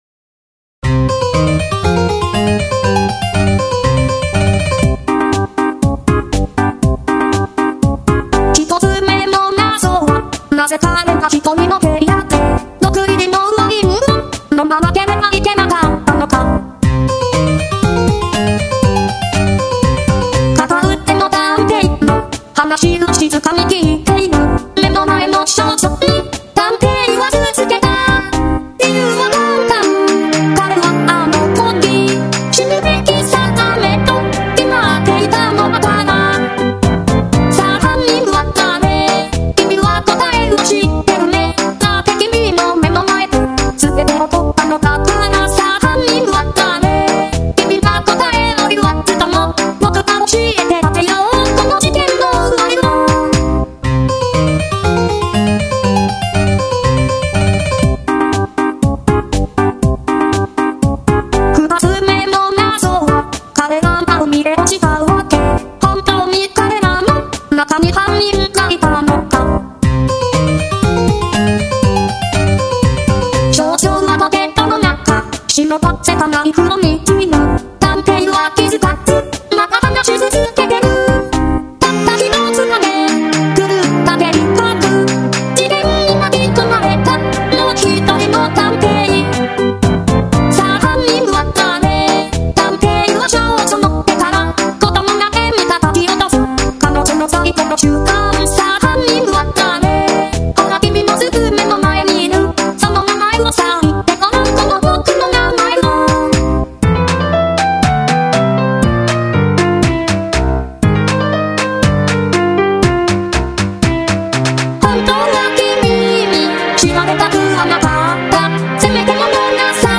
【Vocal / 2010】 mp3 DL ♪